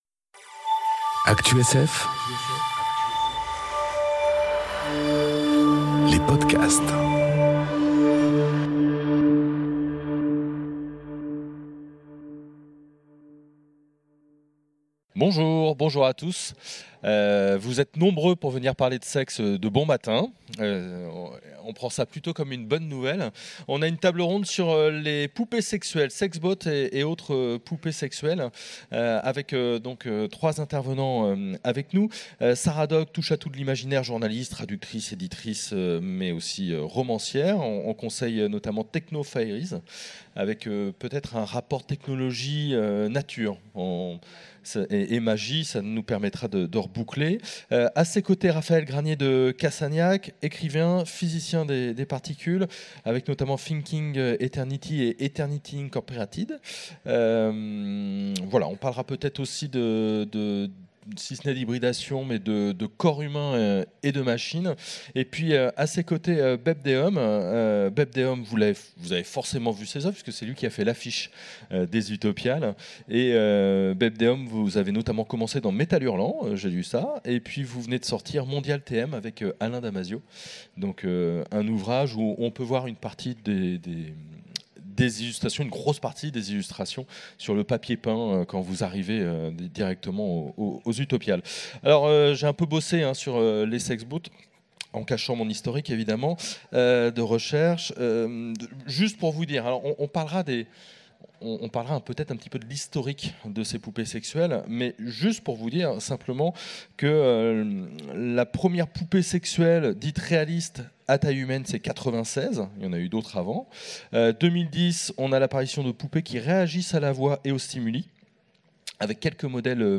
Conférence Sexbot et autres poupées sexuelles enregistrée aux Utopiales 2018